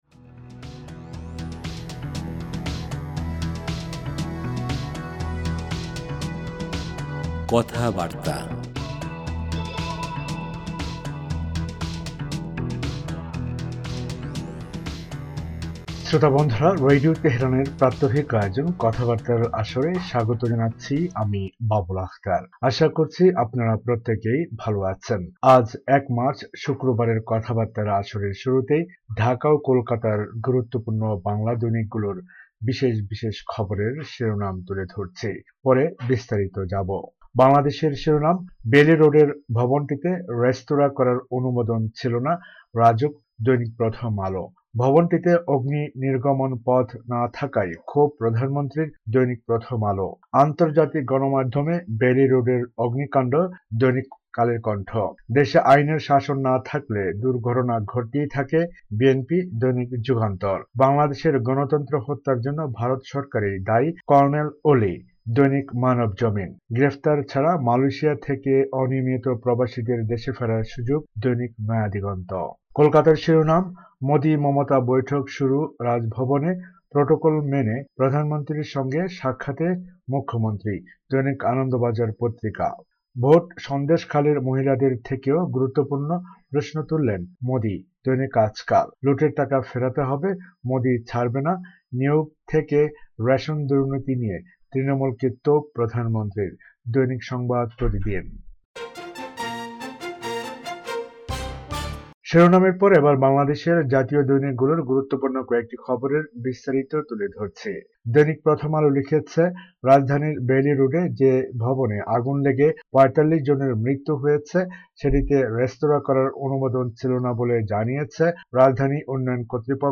পত্রপত্রিকার গুরুত্বপূর্ণ খবর